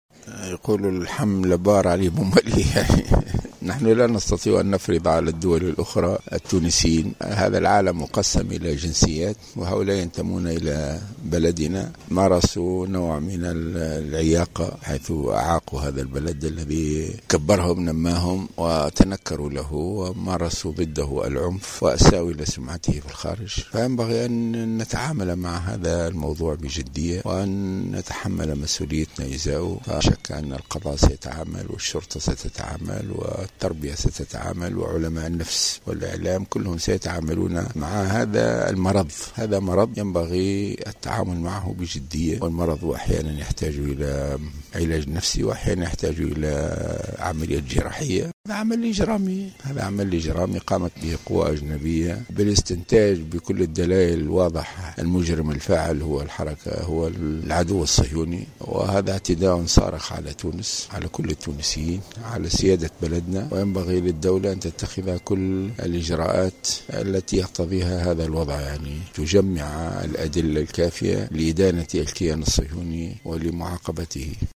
أدى رئيس حركة النهضة الشيخ راشد الغنوشي زيارة إلى ولاية القيروان، اليوم الأحد، مرفوقا بعدد من القيادات الحزبية وبحضور ممثلي الجهة في مجلس نواب.
وقال الغنوشي في تصريح لمراسل الجوهرة أف أم، إنه يتوجب التعامل بجدية مع ملف عودة الإرهابيين التونسيين الذين وصفهم بـ"المرض الذي يحتاج إما علاجا نفسيا أو عملية جراحية لعلاجه" حسب تعبيره.